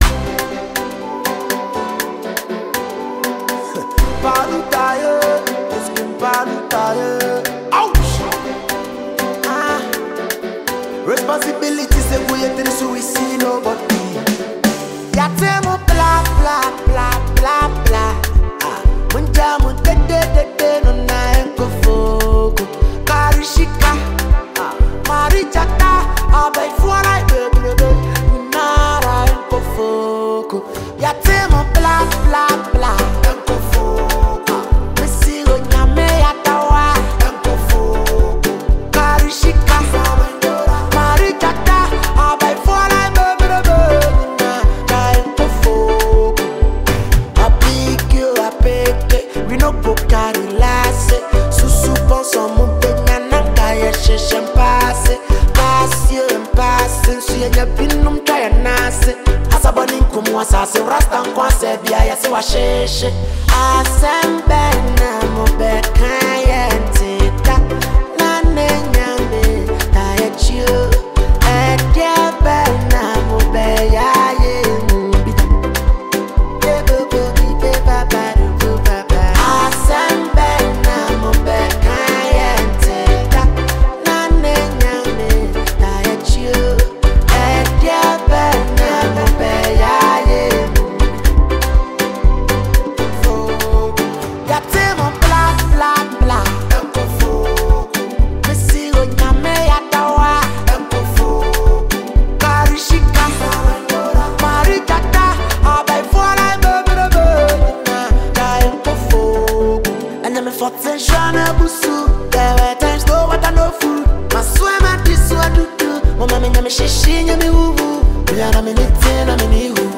fiery street anthem